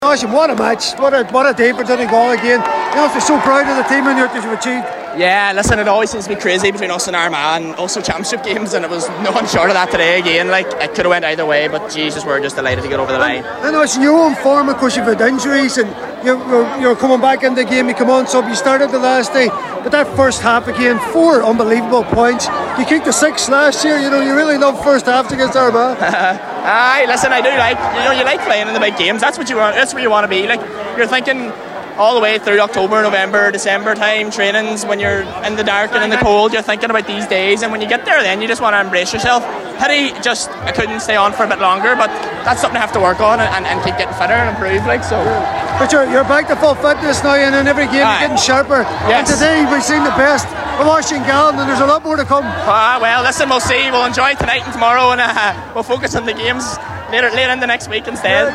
caught up with a number of the Donegal players after an absorbing Ulster SFC decider in Clones